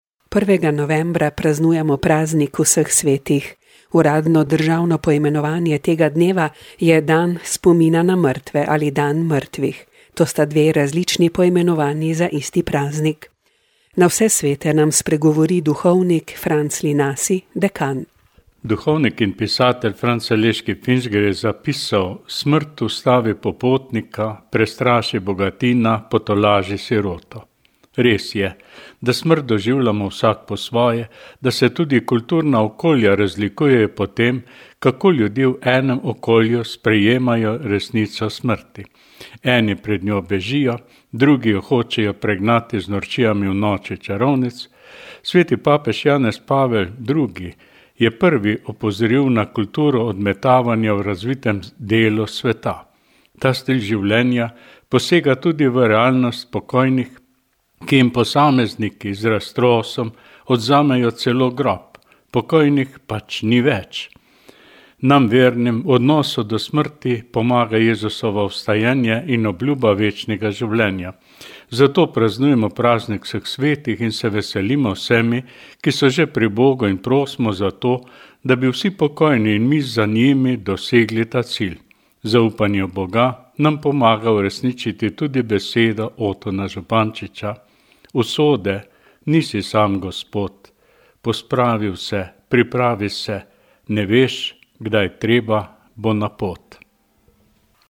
Na vse svete nam spregovori duhovnik